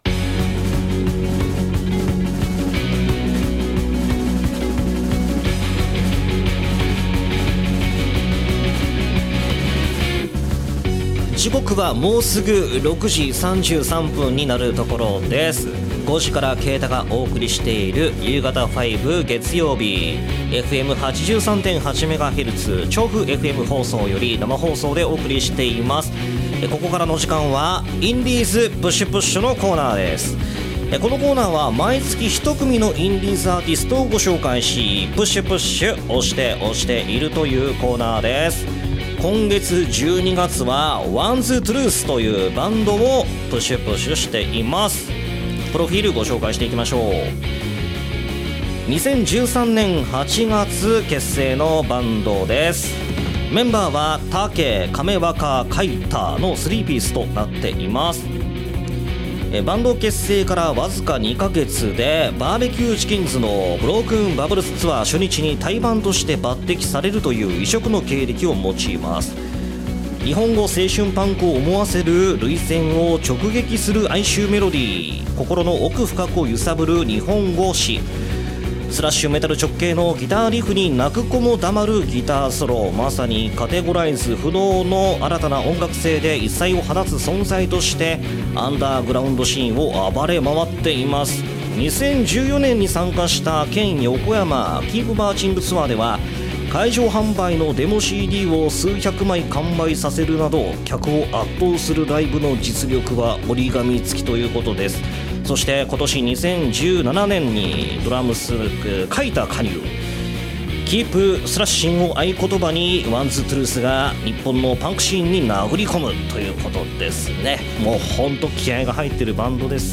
こんな！寒い冬には！熱いロックで暖まりましょう！！！
熱血3ピースロックバンド！！！ デスボが(・∀・)ｲｲﾈ!!と思いきや、聴かせるメロディも持ってまっす！！！